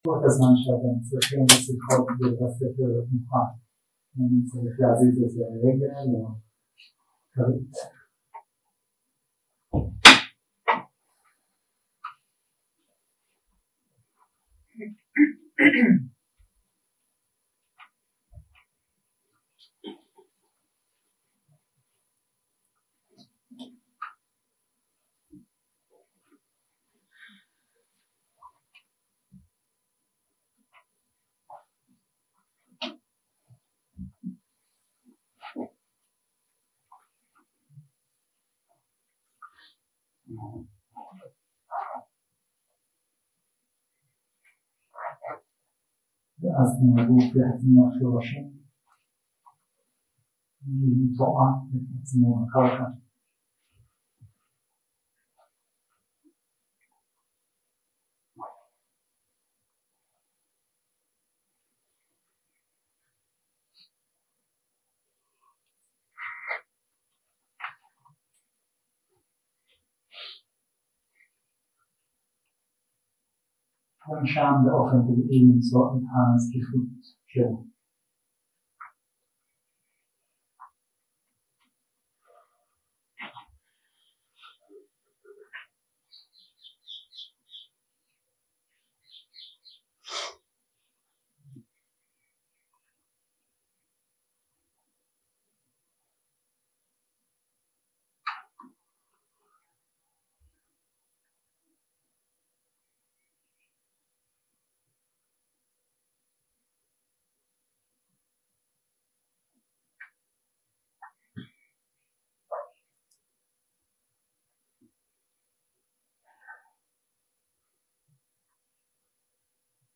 יום 3 – הקלטה 6 – צהריים – מדיטציה מונחית – היפתחות למרחב 0:00 0:00 סוג ההקלטה: Dharma type: Guided meditation שפת ההקלטה: Dharma talk language: Hebrew